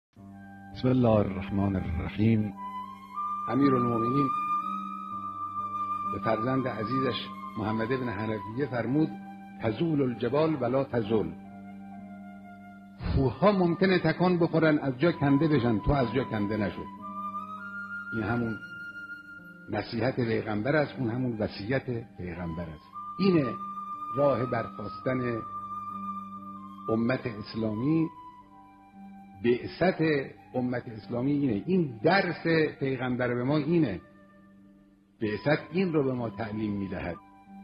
صوت بیانات رهبر انقلاب اسلامی